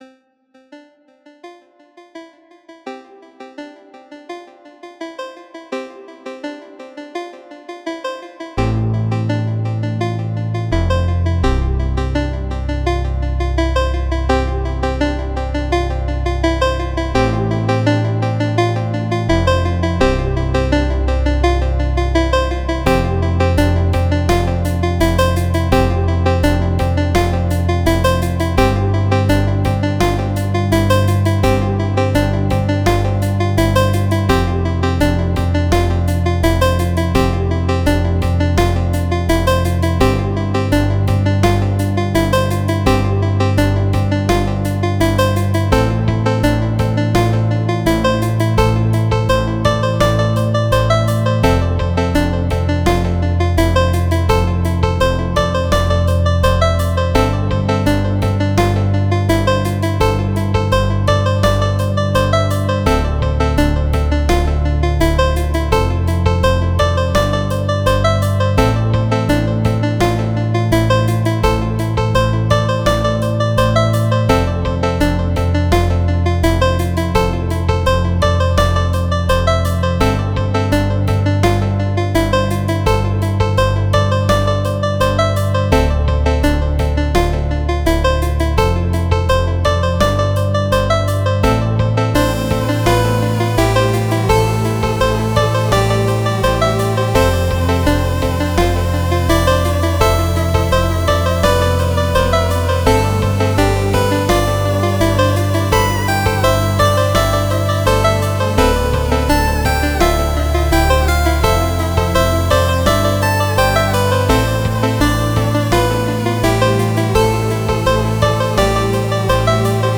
A light chiptune with a slow build up.